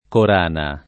[ kor # na ]